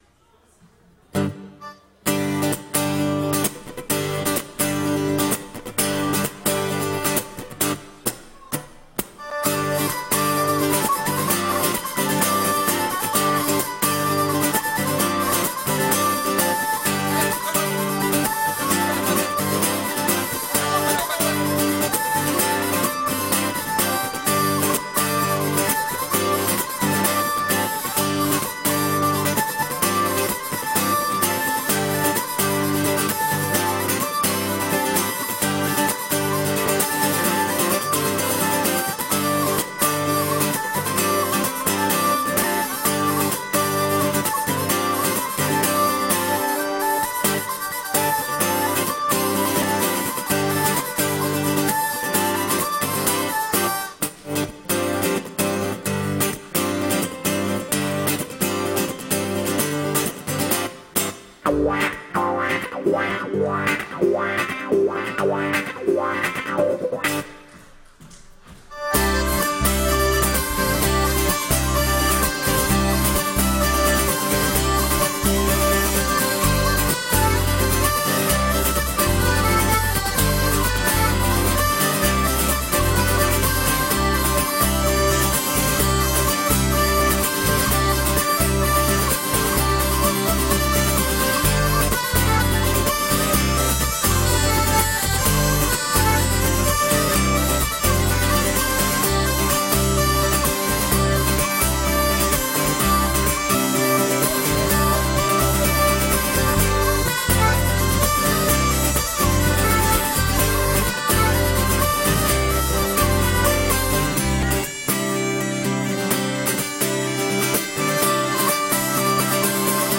Répétition du 11/11/2011 - Module 6: Trégor/Corrèze
lozere_sautière.mp3